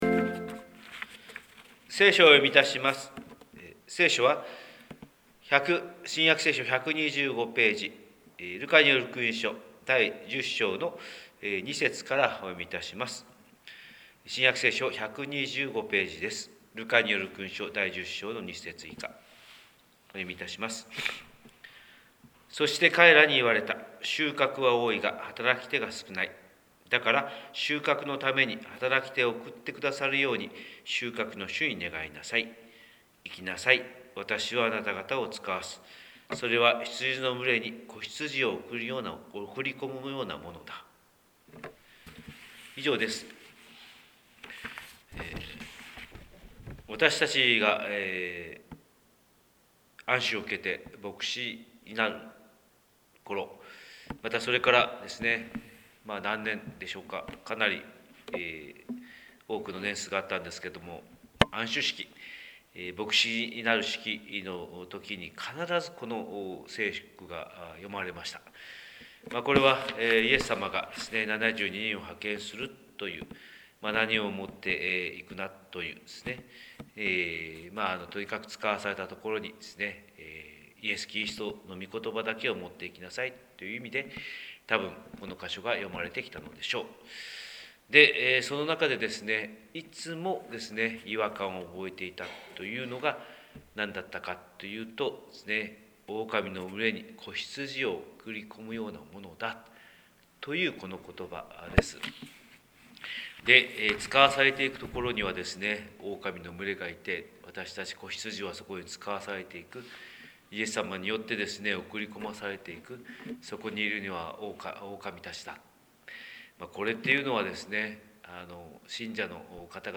神様の色鉛筆（音声説教）: 広島教会朝礼拝250312
広島教会朝礼拝250312「オオカミの中に」